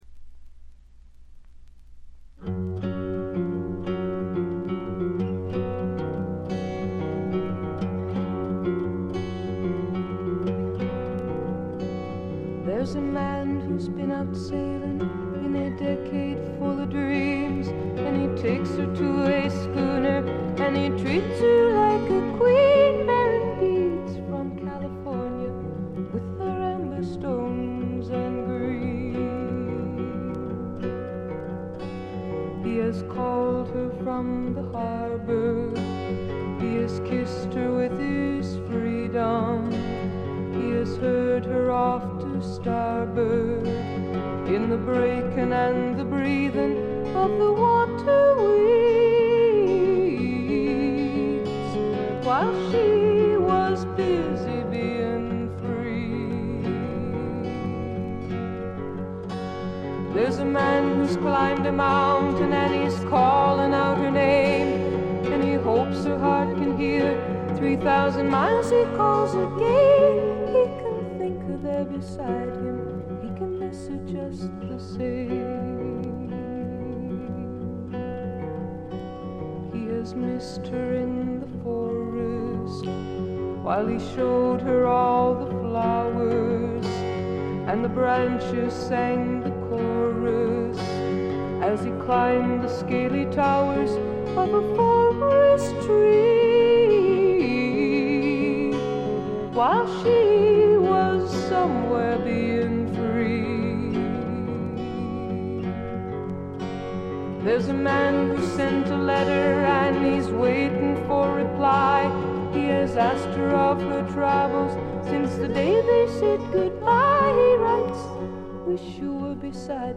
全体にバックグラウンドノイズ。
至上の美しさをたたえたサイケ・フォーク、アシッド・フォークの超絶名盤という見方もできます。
試聴曲は現品からの取り込み音源です。
guitar, piano, vocals